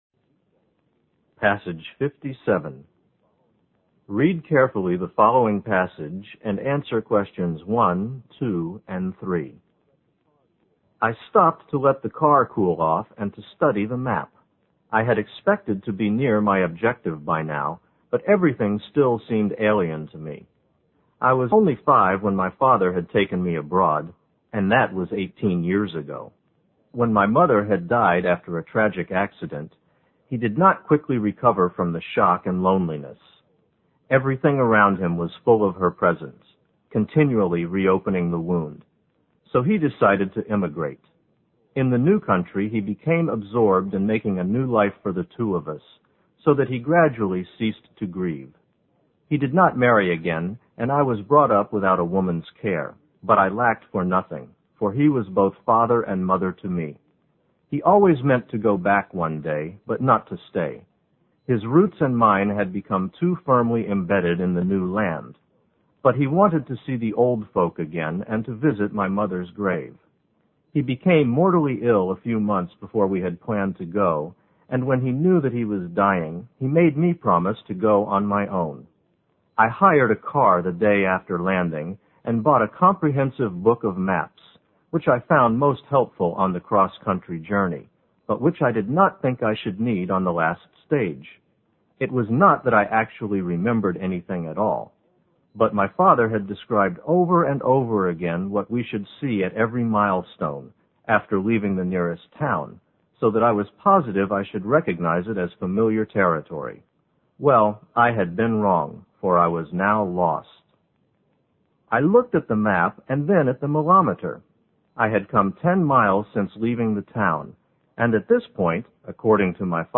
新概念英语85年上外美音版第三册 第57课 听力文件下载—在线英语听力室